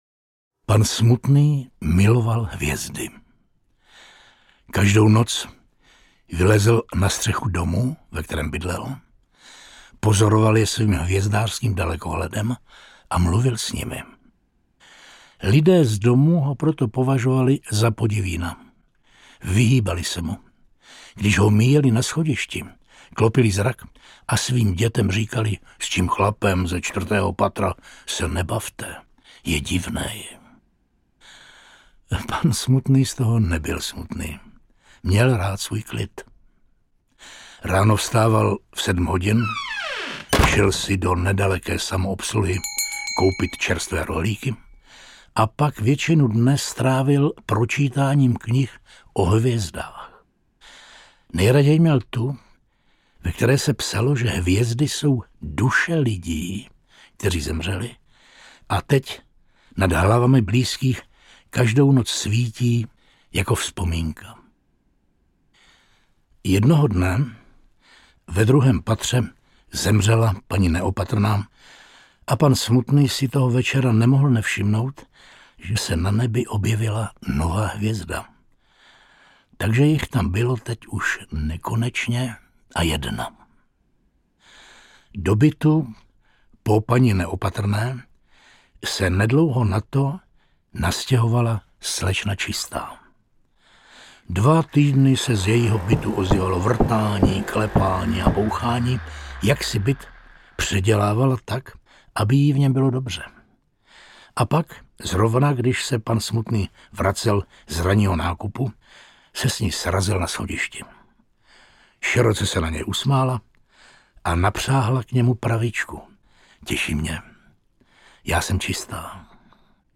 Pohádky pro dospělé děti a nedospělé dospělé audiokniha
Ukázka z knihy
Čte Jan Kačer.
• InterpretJan Kačer